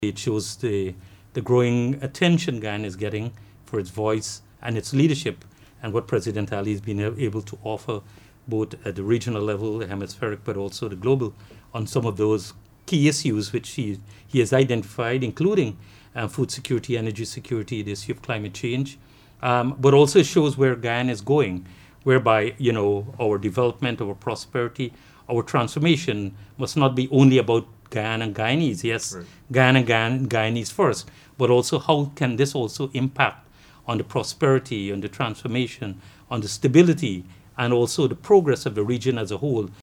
Foreign Secretary Robert Persaud spoke with NCN about the importance of these engagements, emphasizing the proud moment for Guyana as it plays host to the third installment of the energy conference while preparing for the Caricom Heads of Government conference from February 25 to 28.